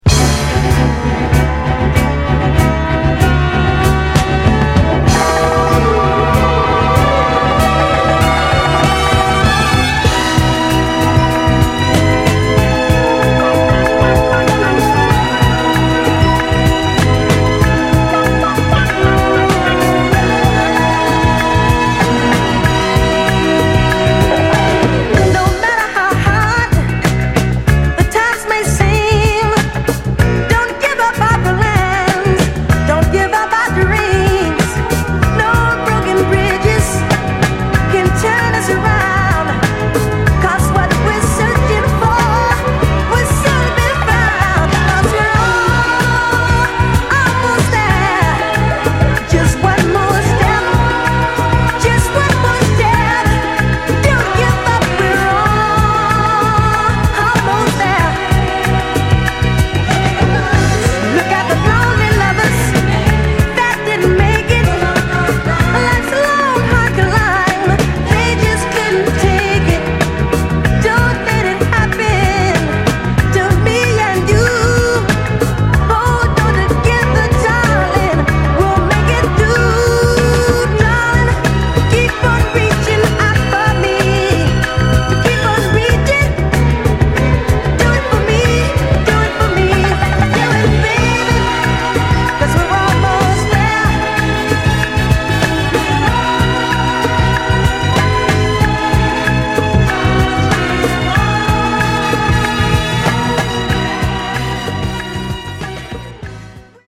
こみ上げ哀愁クロスオーヴァー・ソウル人気作！